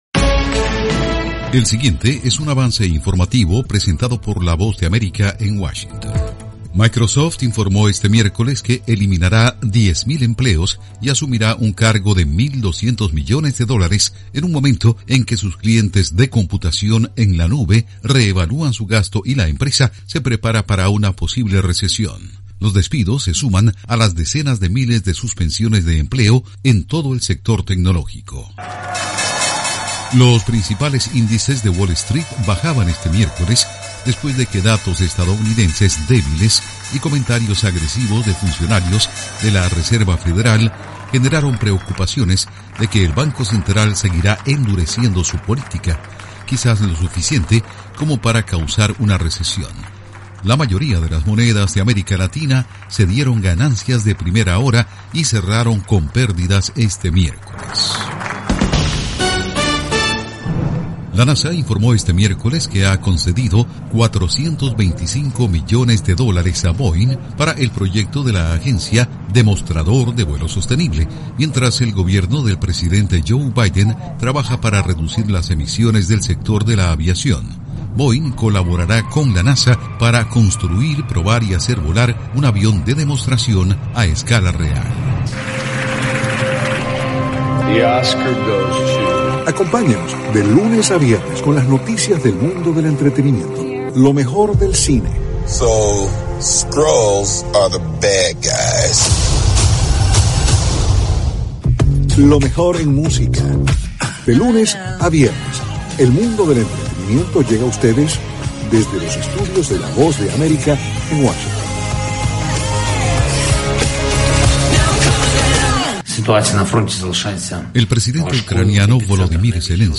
El siguiente es un avance informativo presentado por la Voz de América en Washington.